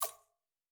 TC PERC 07.wav